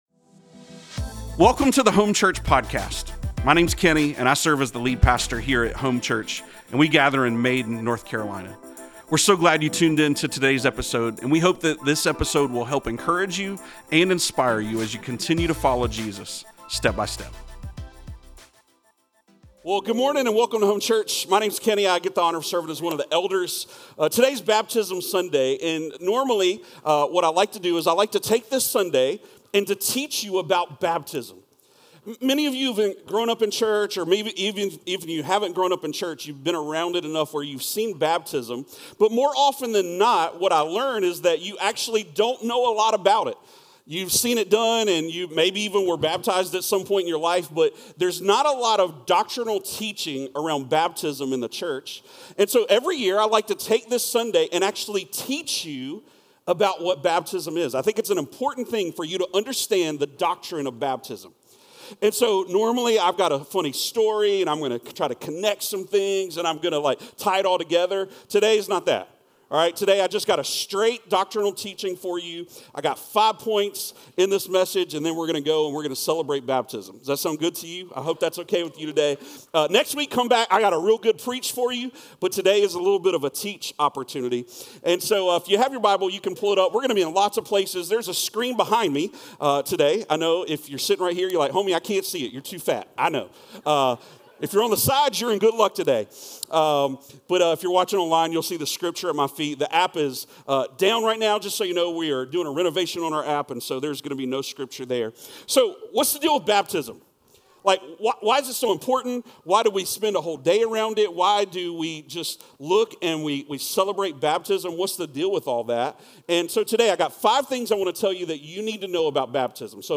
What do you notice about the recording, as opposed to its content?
Baptism Sunday